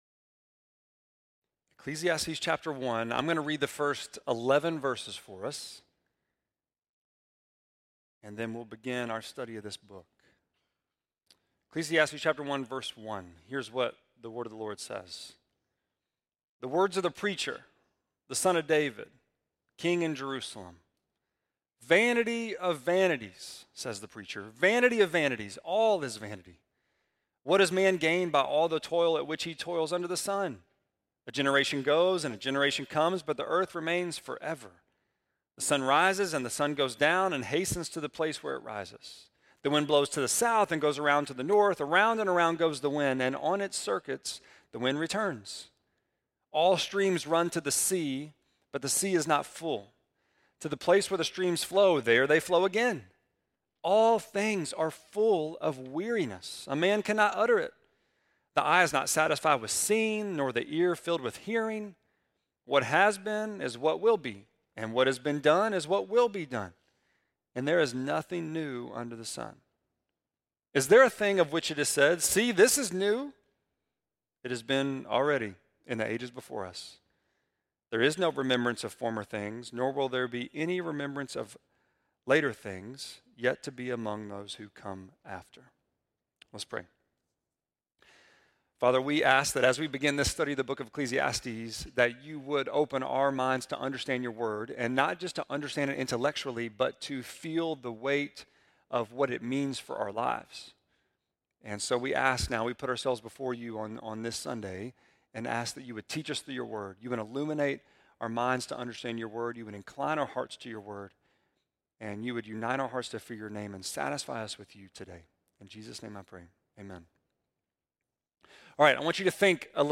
1.5-sermon.mp3